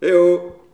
Les sons ont été découpés en morceaux exploitables. 2017-04-10 17:58:57 +02:00 128 KiB Raw History